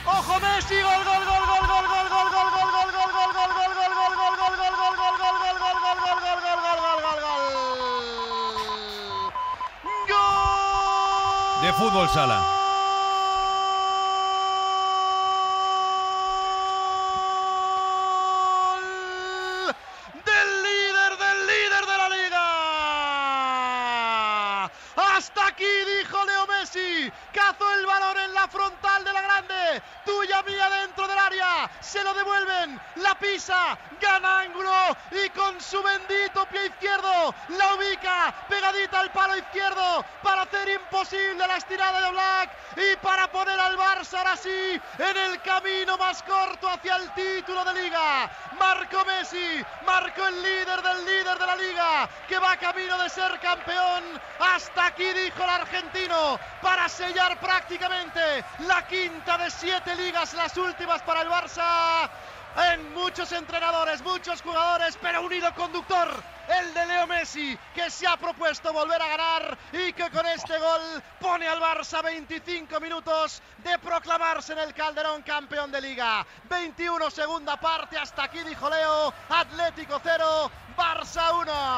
Transmissió del partit de la lliga de futbol masculí entre l'Atlético de Madrid i el Futbol Club Barcelona.
Esportiu